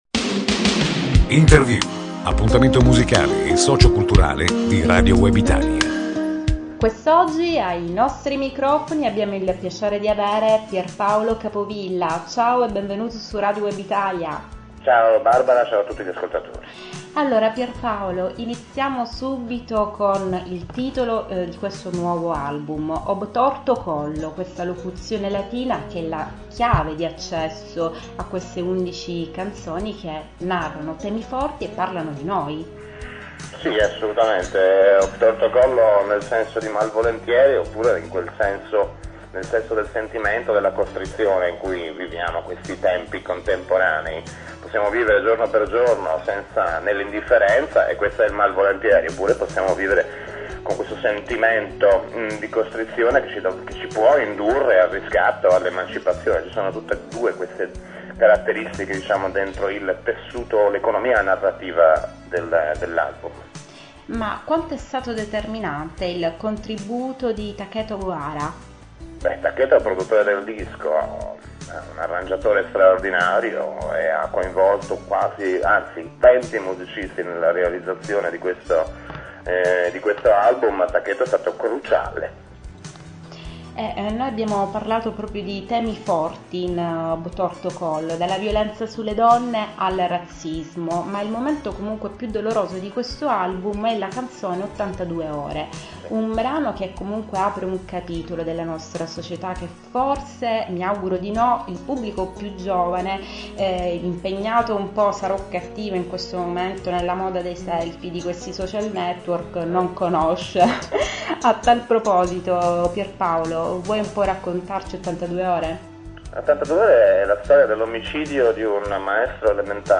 Pierpaolo Capovilla ospite di “Interview” – appuntamento musicale di Radio Web Italia – presenta l'a
Pierpaolo-capovilla-intervista-2.mp3